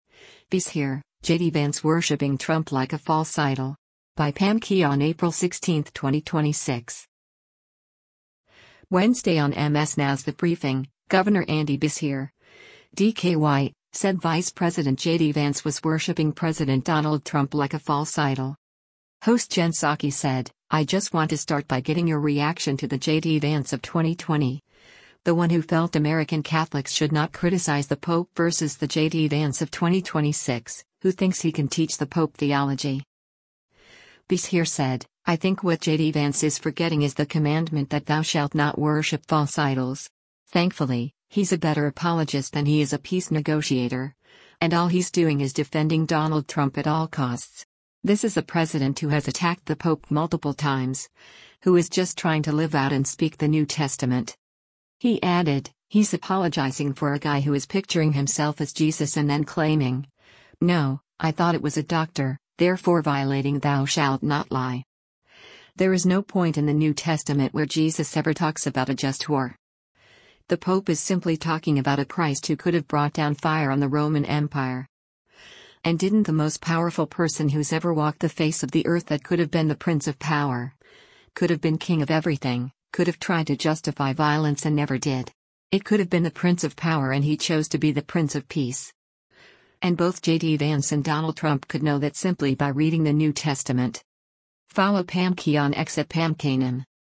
Wednesday on MS NOW’s “The Briefing,” Gov. Andy Beshear (D-KY) said Vice President JD Vance was worshiping President Donald Trump like a false idol.